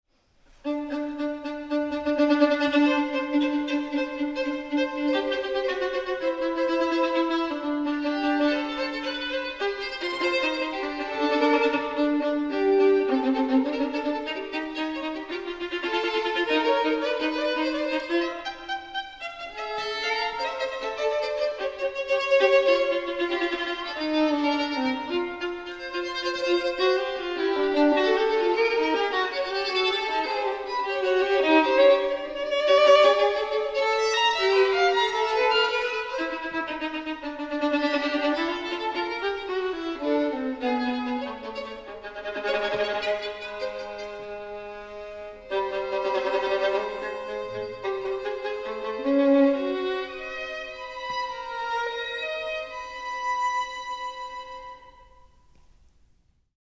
violons